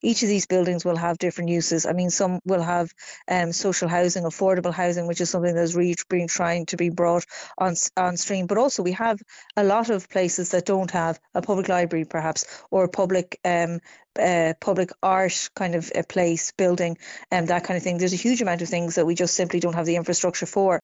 Sinn Fein’s Spokesperson for Public Expenditure Máiréad Farrell says these buildings could be put to use: